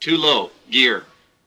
A320-family/Sounds/GPWS/too-low-gear.wav at 489d20e09bdf3f61ea6fc5cf95a0582fb8c9e213
too-low-gear.wav